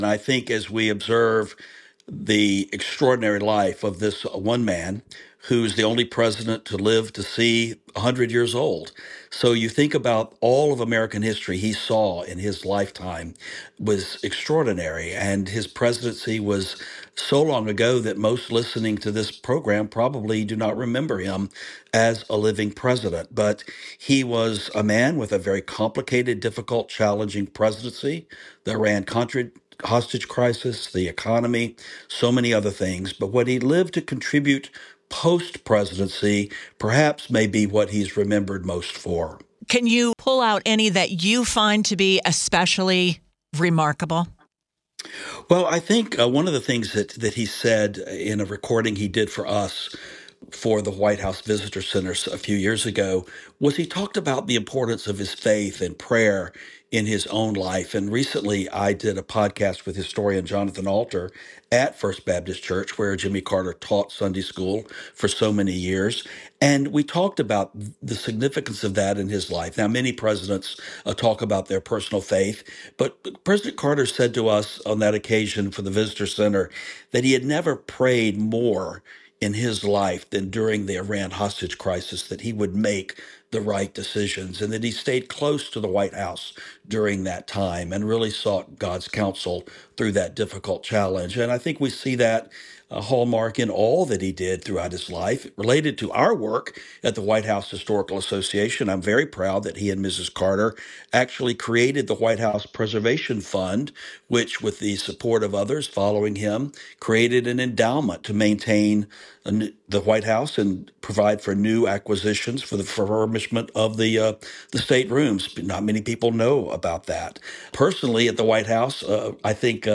WTOP spoke with historians, political reporters and those who personally knew Carter as the nation mourned his death.